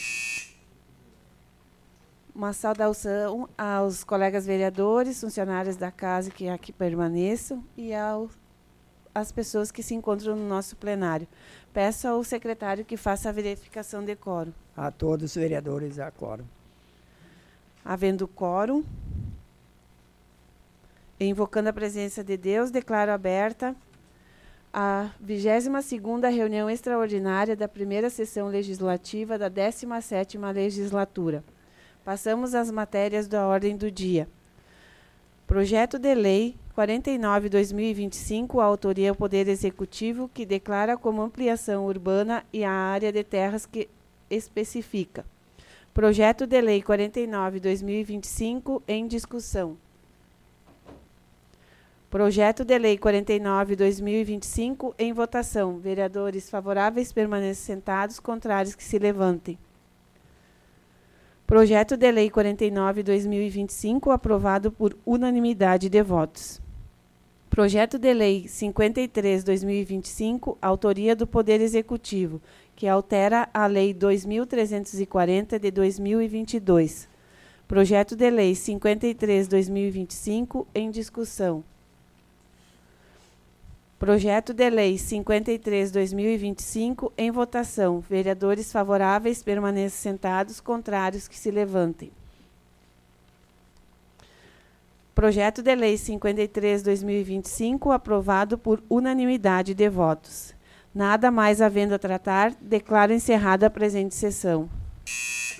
Áudio da 22ª Sessão Plenária Extraordinária da 17ª Legislatura, de 25 de agosto de 2025